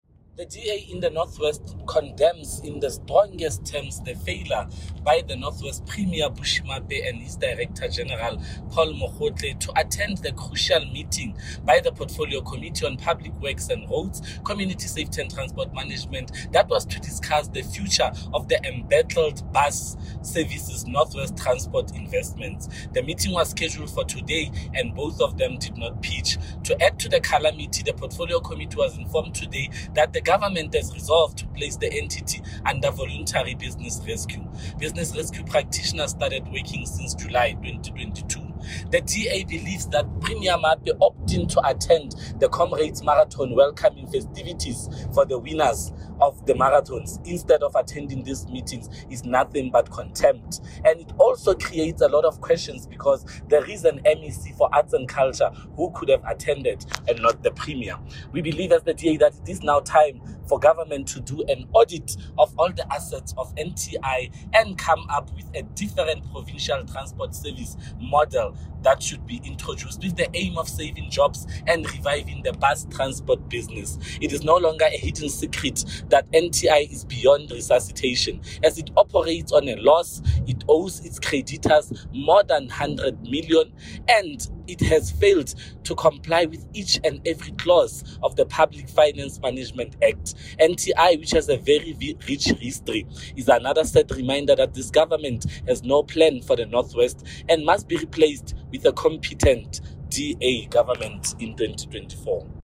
Issued by Freddy Sonakile MPL – DA North West Spokesperson on Public Works & Roads, Community Safety and Transport Management
Note to Editors: Please find the attached soundbite in